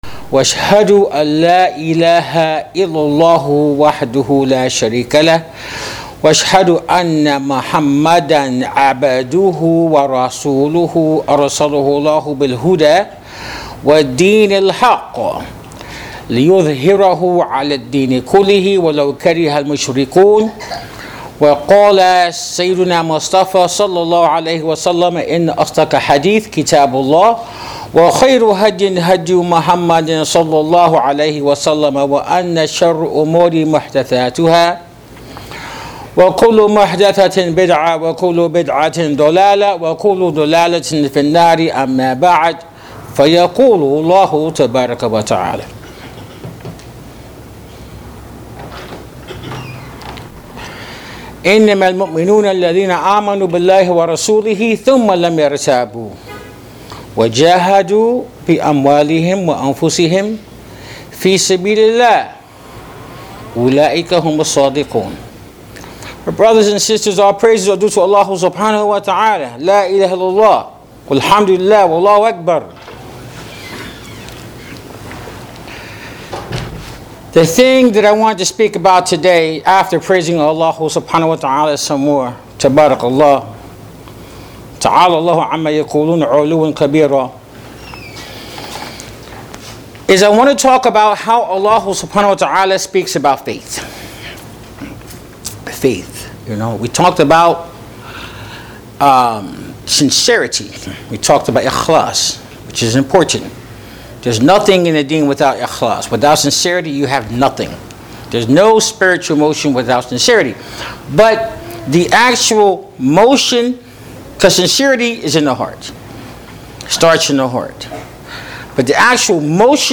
Faith in Islam is validated by action. This is the topic of this khutbatul Jum’ah.